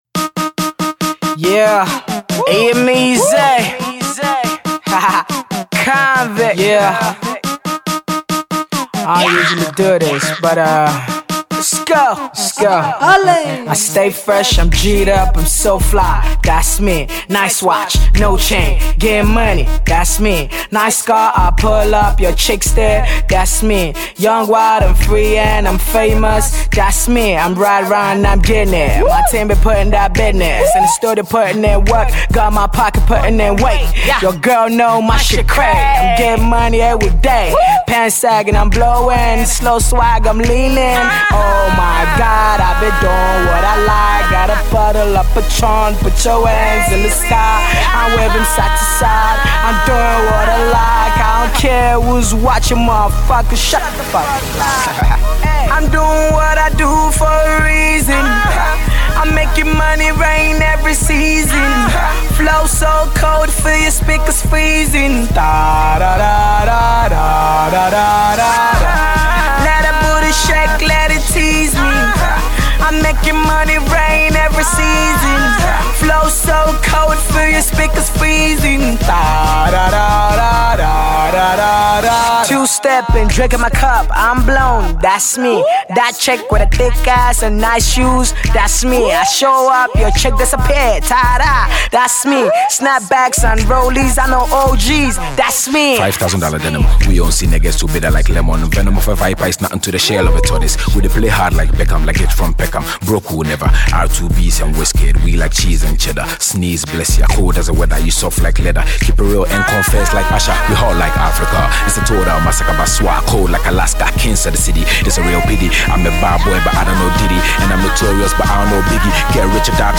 some nice production